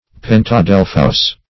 Definition of pentadelphous.
pentadelphous.mp3